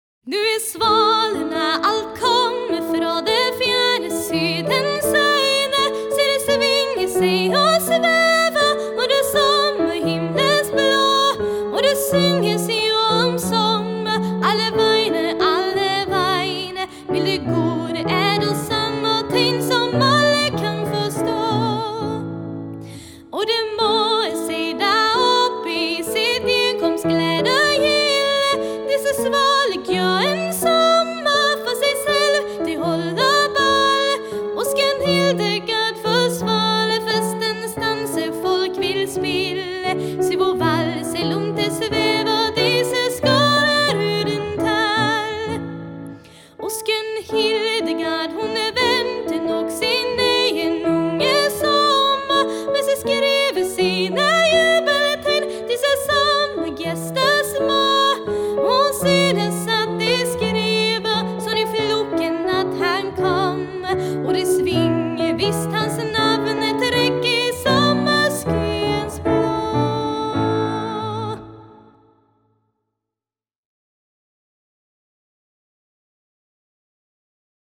Piano och arr